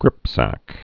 (grĭpsăk)